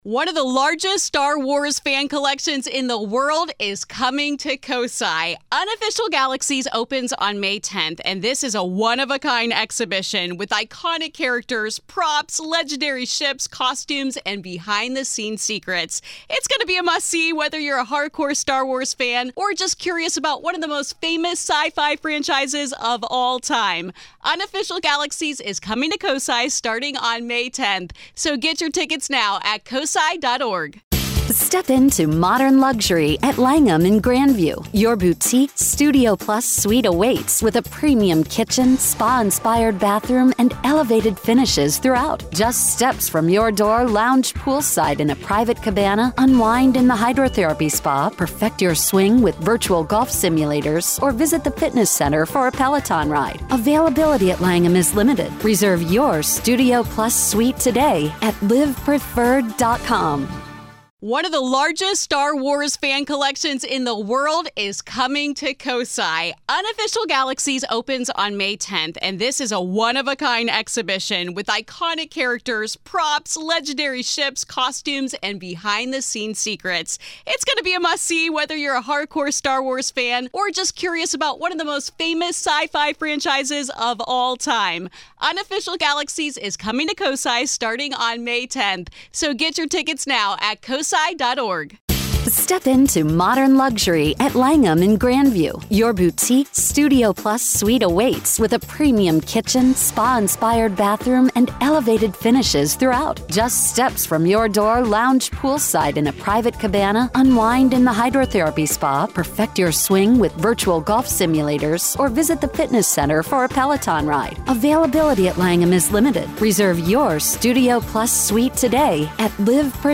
In this episode, a listener shares a haunting experience from their time living in an apartment with a dark history. The story begins with strange occurrences, from a mysterious figure pacing the halls late at night to a rocking chair moving on its own.
Grave Confessions is an extra daily dose of true paranormal ghost stories told by the people who survived them!